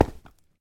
step / stone6.ogg
stone6.ogg